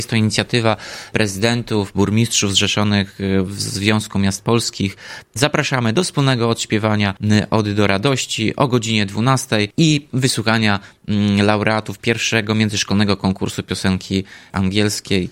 Mówi Tomasz Andrukiewicz, prezydent Ełku.